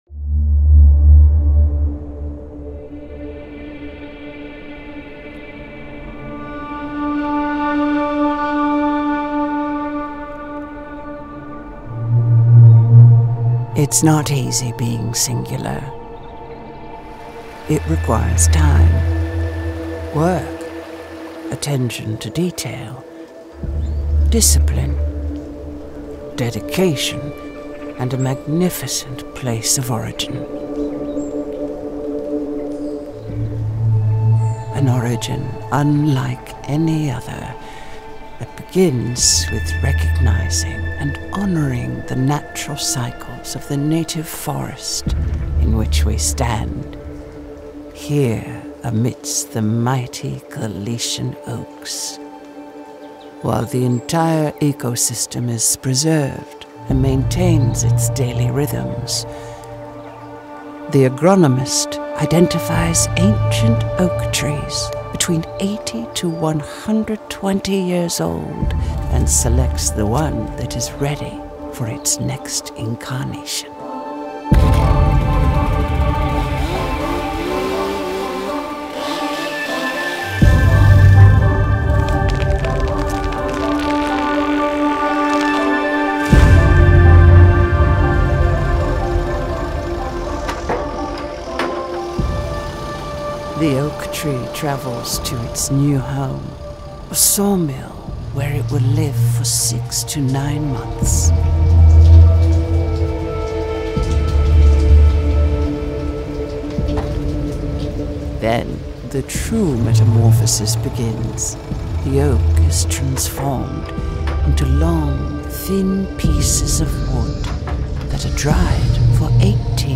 Documentary voice over narration requires a talent with wonderful storytelling abilities and great stamina.